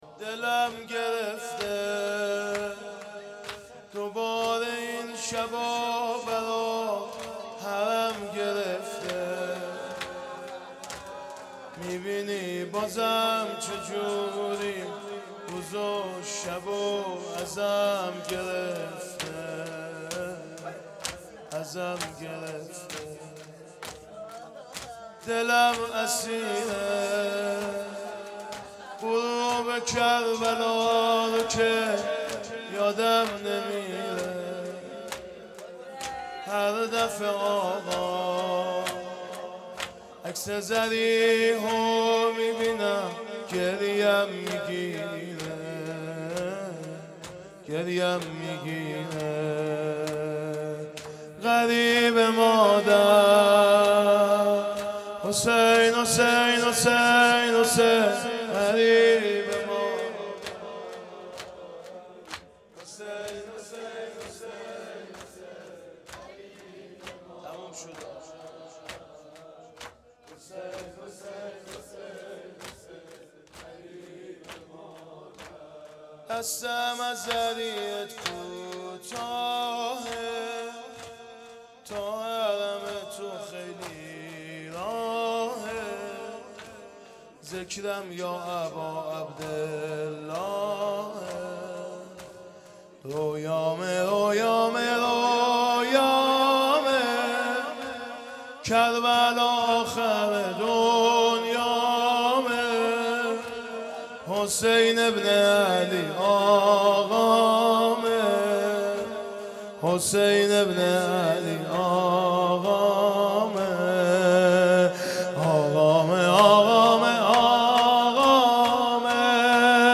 شور
محرم 1440 _ شب دهم